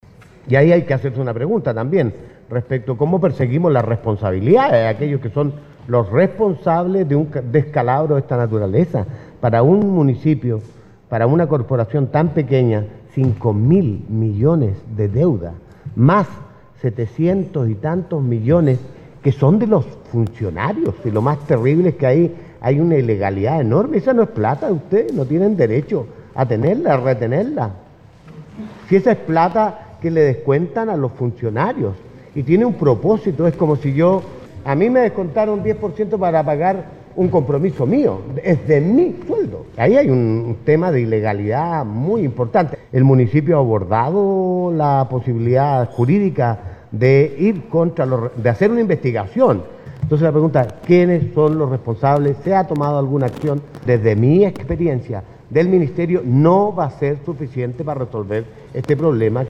Dispares opiniones generó entre autoridades y parlamentarios la presentación que la delegación de Ancud realizó ante la comisión de Educación de la Cámara de Diputados, en la jornada del lunes, en Santiago.
El diputado de la Democracia Cristiana Mario Venegas solo centró su alocución en la persecución de responsabilidades, de quienes causaron este enorme “forado” financiero en administraciones anteriores.